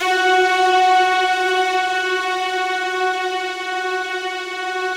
BIGORK.F#3-R.wav